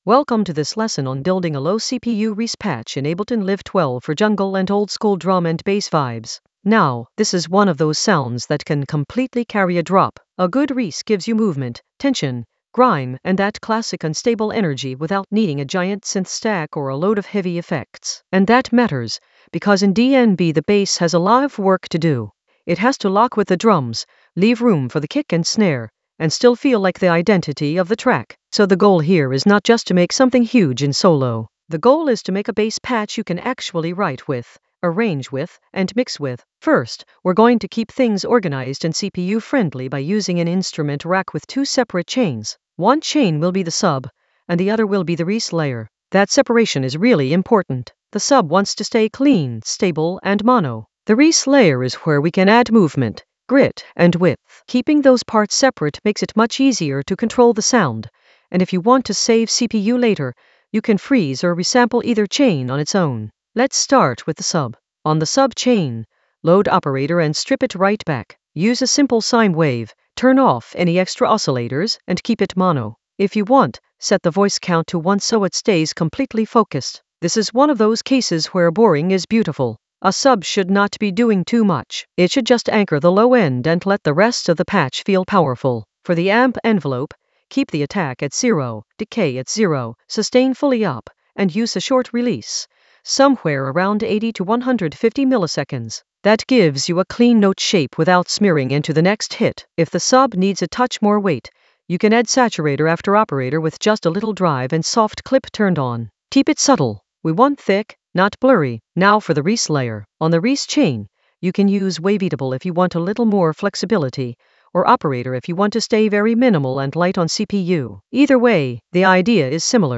An AI-generated intermediate Ableton lesson focused on Compose a reese patch with minimal CPU load in Ableton Live 12 for jungle oldskool DnB vibes in the Sound Design area of drum and bass production.
Narrated lesson audio
The voice track includes the tutorial plus extra teacher commentary.